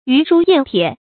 鱼书雁帖 yú shū yàn tiē 成语解释 泛指书信。
ㄧㄩˊ ㄕㄨ ㄧㄢˋ ㄊㄧㄝ